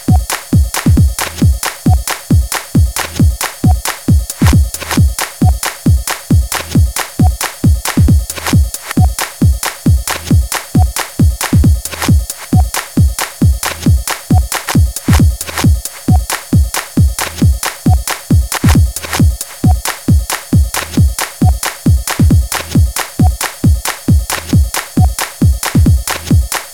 描述：little vocals made with a text to speach synth
声道立体声